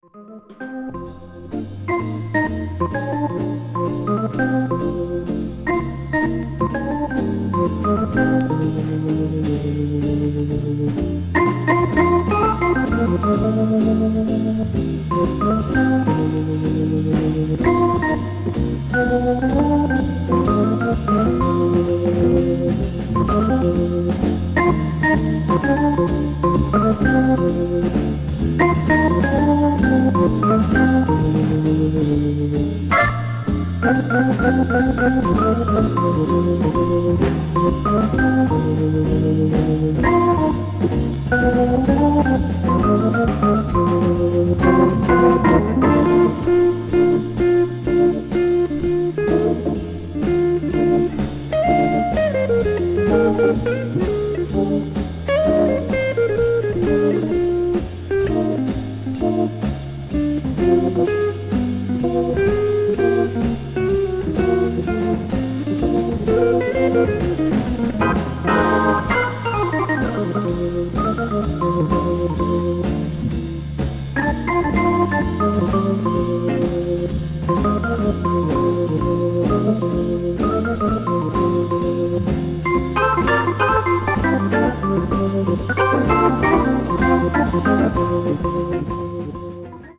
This album features orchestral arrangements of popular songs
jazz standard   modern jazz   organ jazz   soul jazz